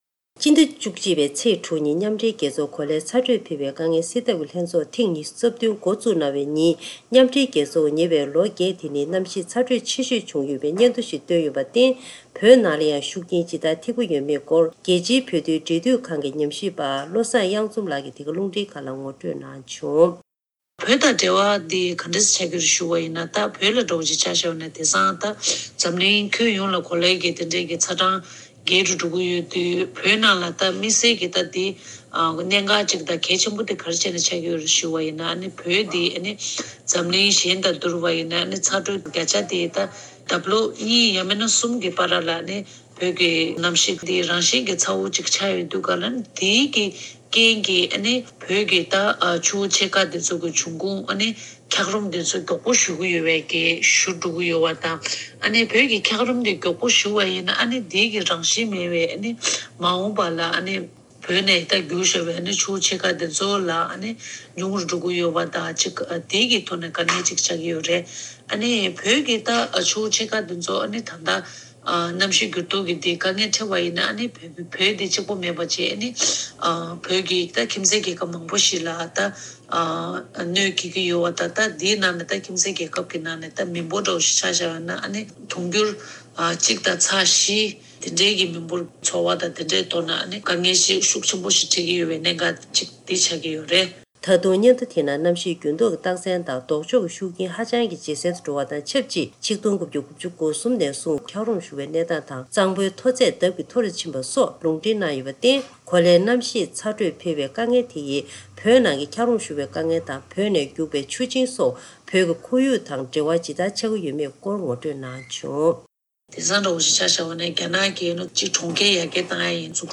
ཐེངས་འདིའི་གསར་འགྱུར་དཔྱད་གཏམ་གྱི་ལེ་ཚན་ནང་།